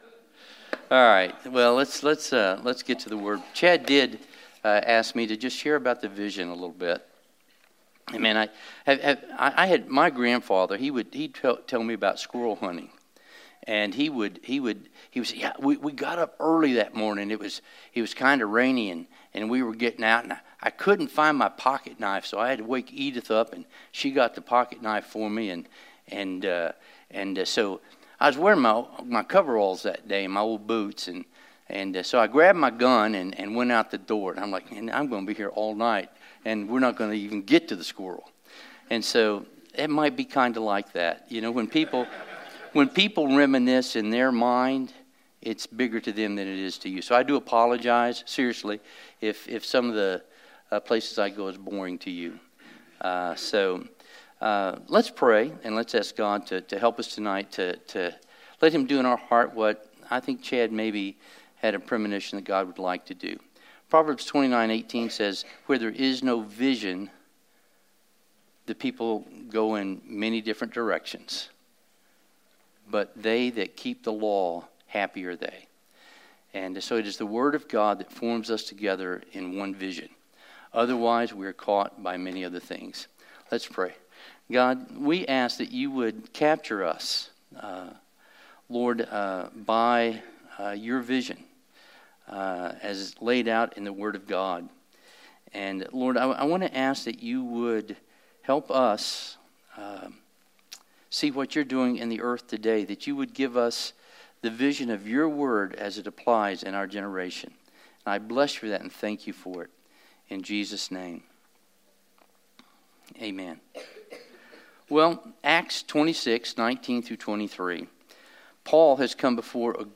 Sermon 5/3: Vision of CF Churches